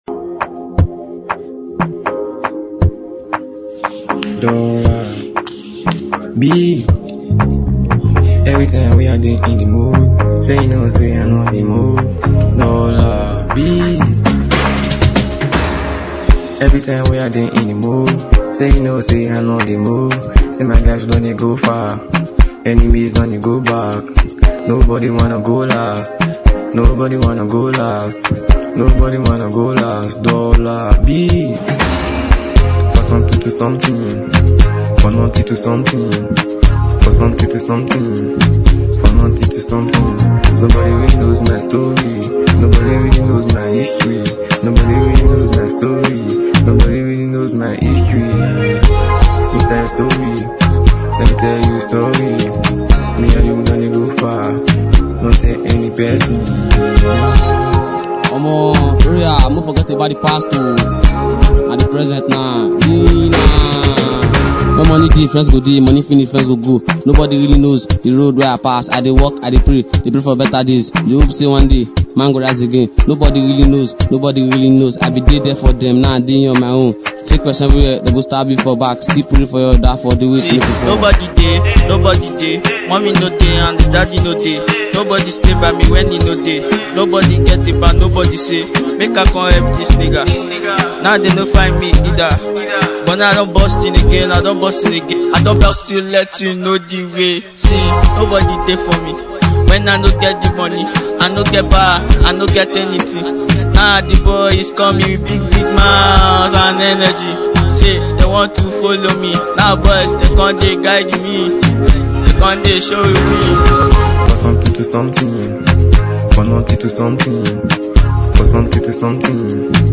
a Nigerian rapper